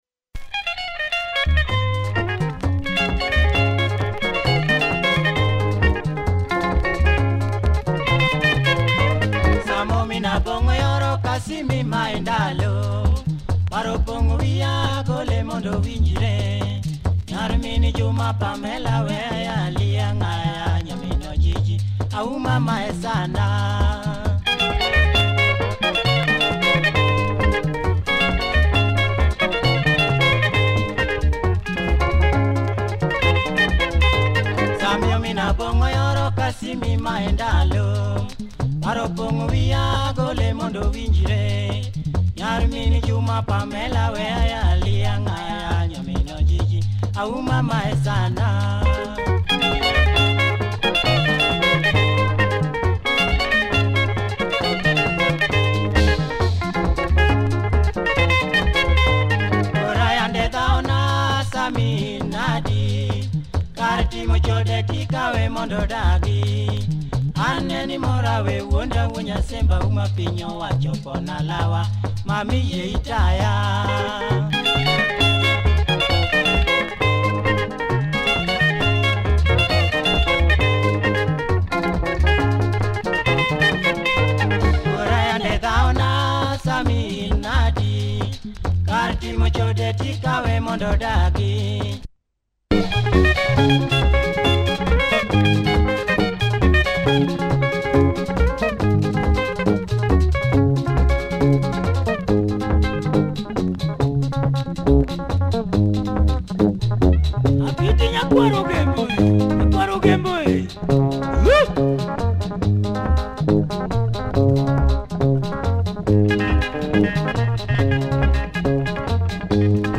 larger band this time